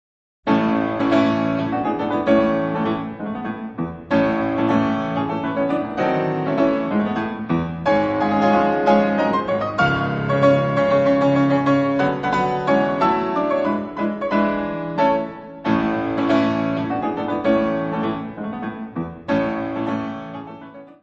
piano
Music Category/Genre:  Classical Music